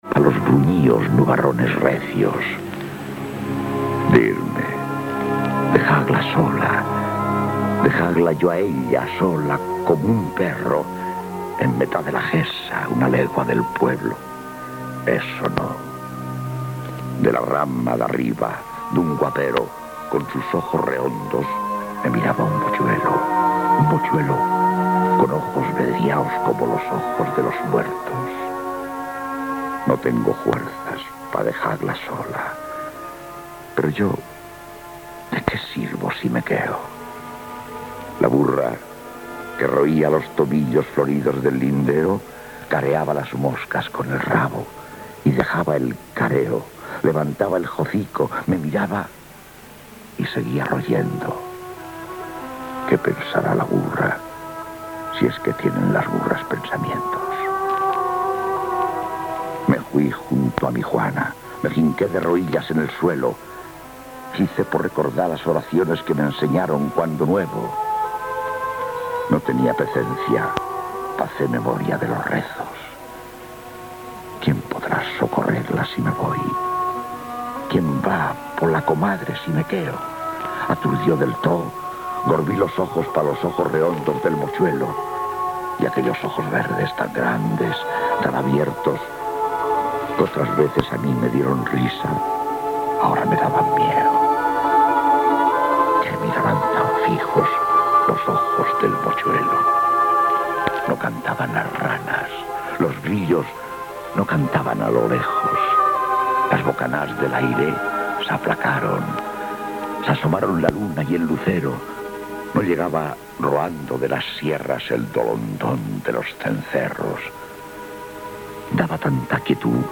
Lectures d'unes poesies i sintonia final amb el nom del programa